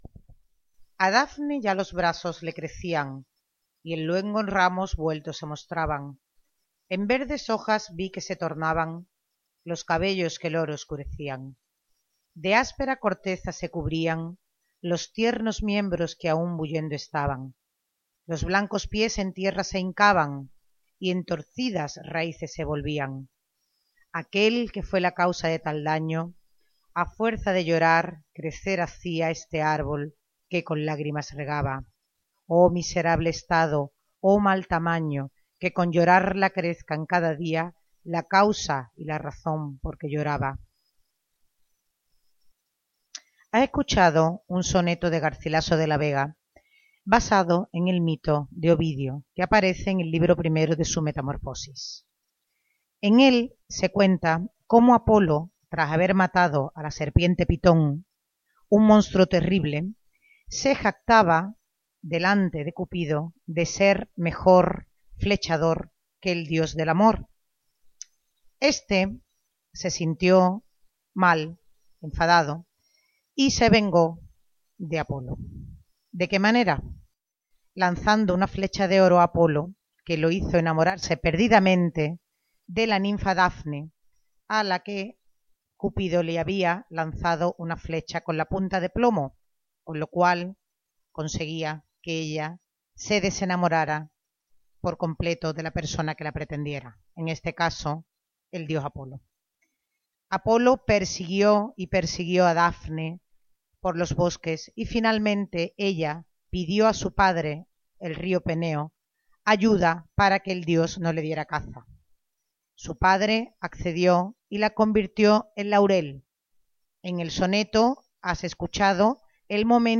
Escucha este poema de Garcilaso de la Vega basado en el relato de Ovidio acerca del mito de Apolo y Dafne.